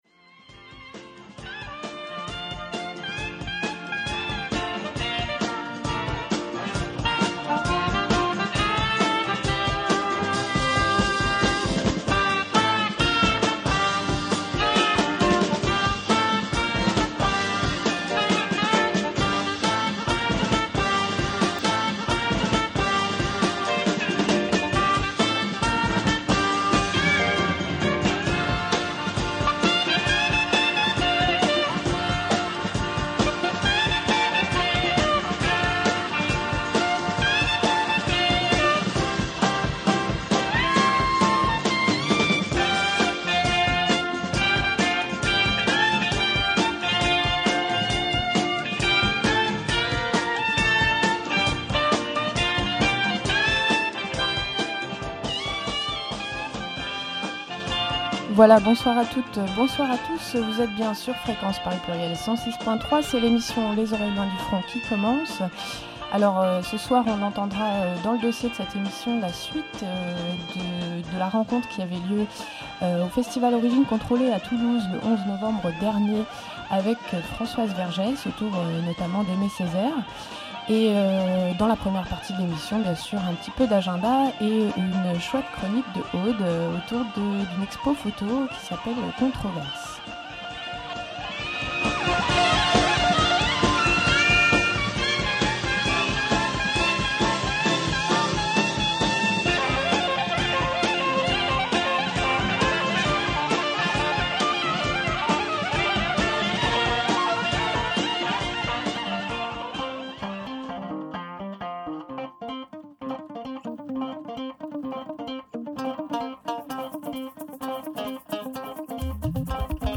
L’occasion d’aborder les réflexions d’Aimé Césaire sur le colonialisme, l’esclavage et les questions d’histoire et de mémoire. Cette rencontre a été enregistrée le 11 novembre 2008 à Toulouse, lors du dernier festival Origines Contrôlées organisé par le Tactikollectif.